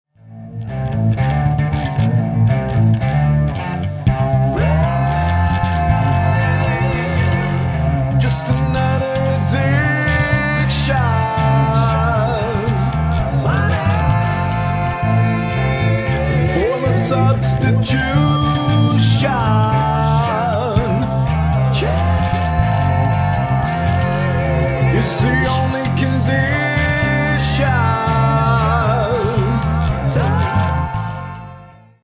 A play-along track in the style of rock, fusion.